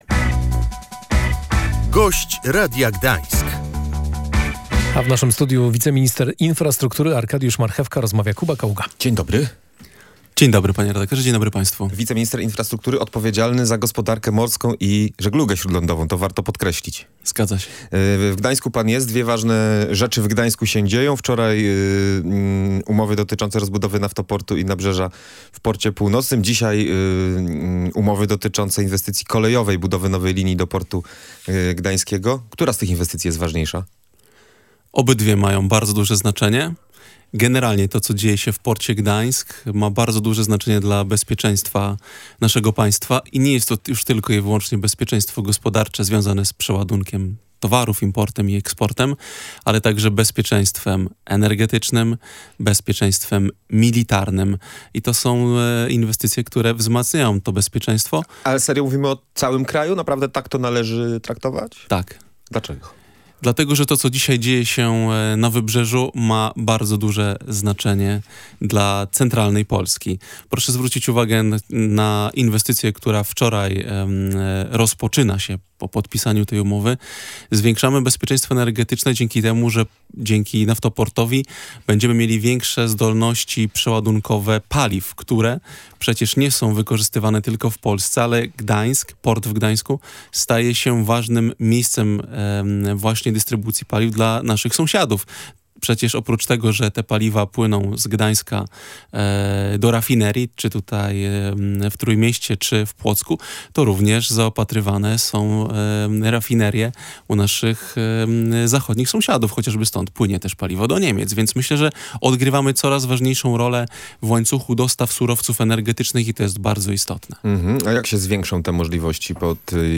Armatorzy rybołówstwa rekreacyjnego w ciągu kilku tygodni dostaną wsparcie finansowe - zapewnił w Radiu Gdańsk wiceminister infrastruktury Arkadiusz Marchewka.